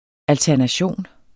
Udtale [ altæɐ̯naˈɕoˀn ]